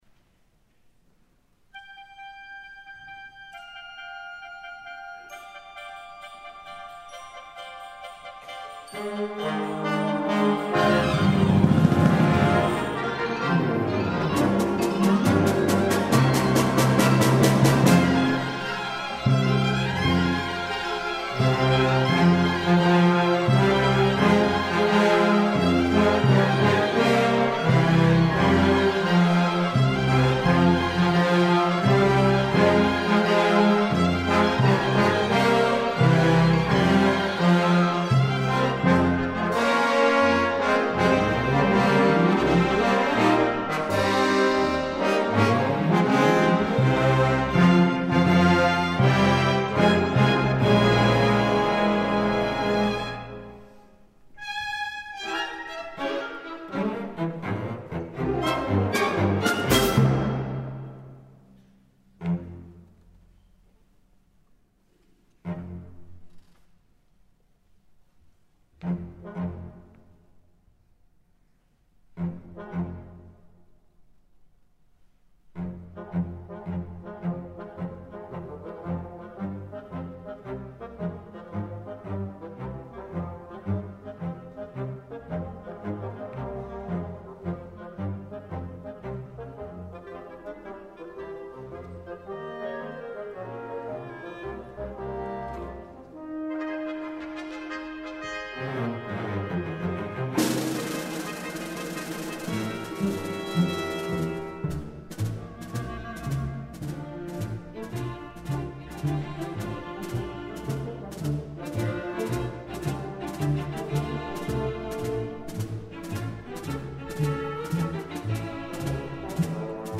Voicing: Full Orchestra